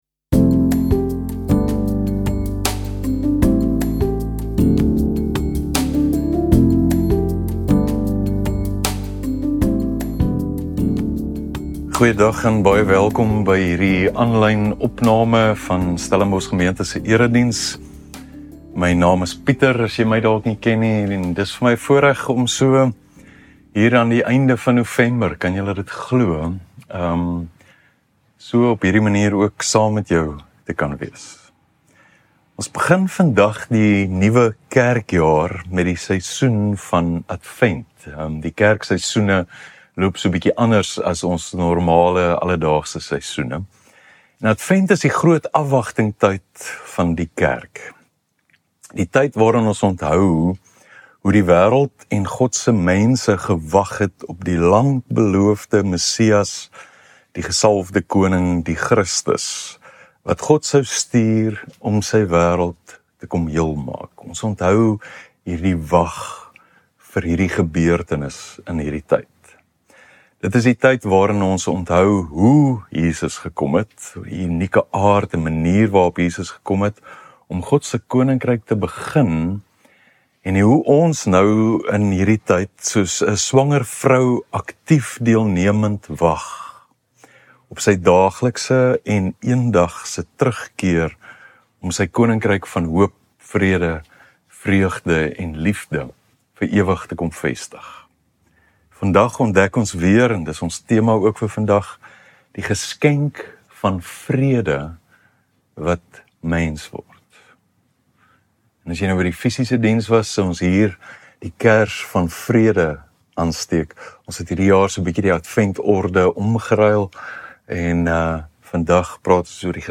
Stellenbosch Gemeente Preke